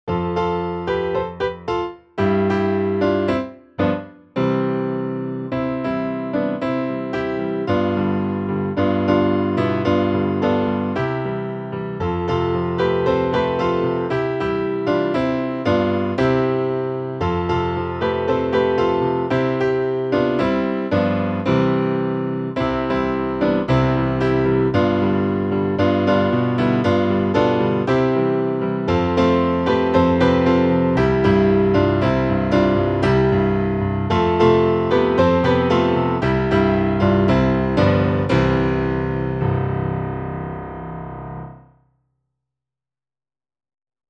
Beskyde-beskyde-C-Dur.mp3